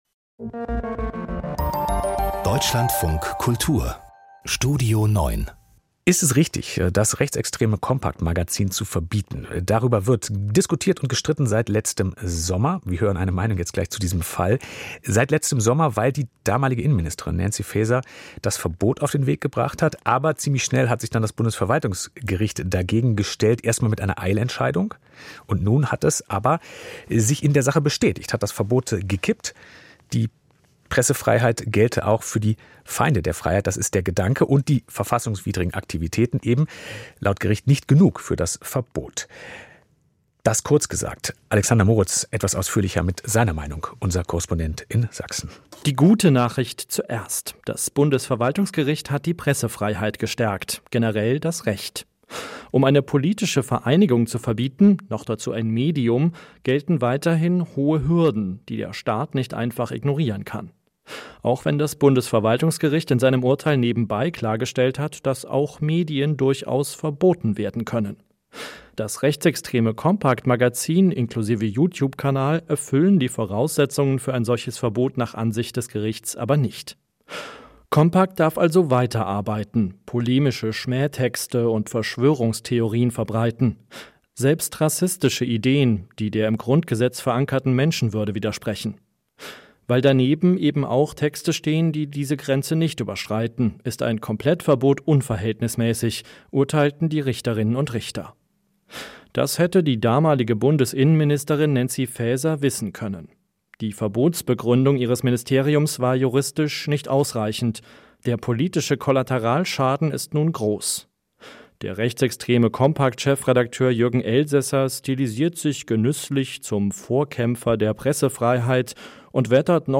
Kommentar: Compact-Urteil stärkt Pressefreiheit